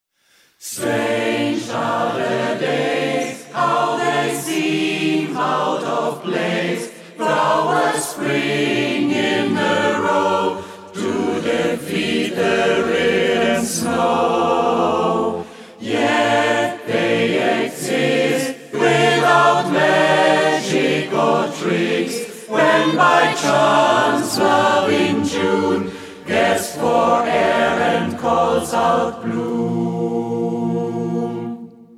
Swinging